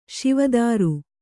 ♪ Śiva dāru